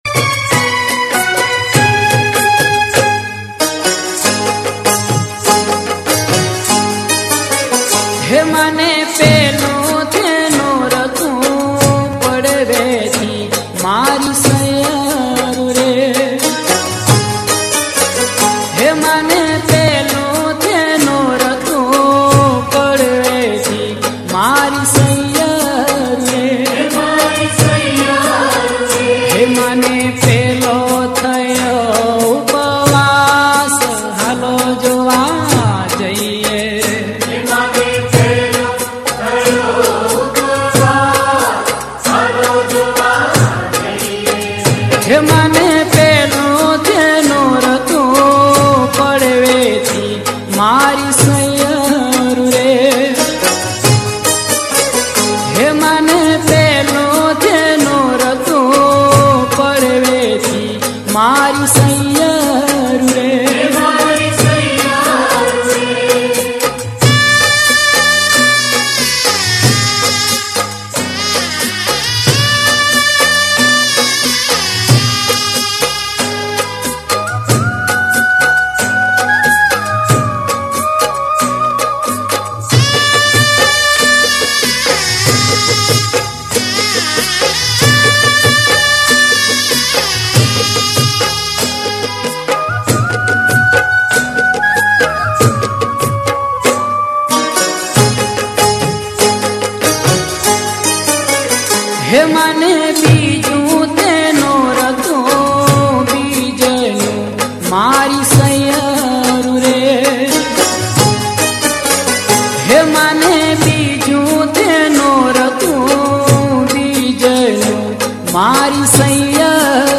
Non Stop Dandiya Mix